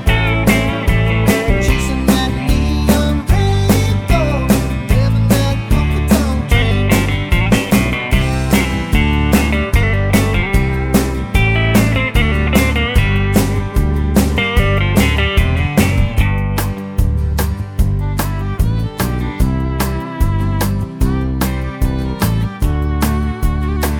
no Backing Vocals Country (Male) 3:03 Buy £1.50